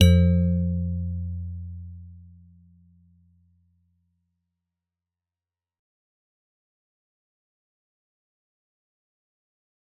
G_Musicbox-F2-f.wav